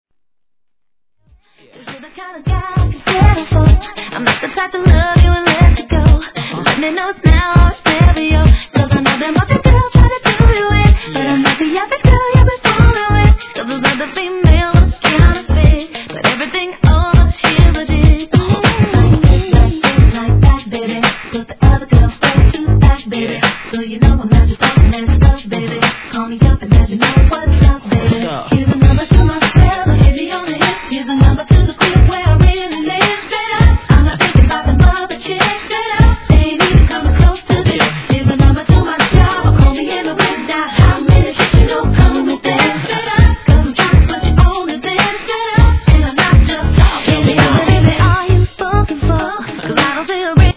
HIP HOP/R&B
スムース・ダンシングナンバー！！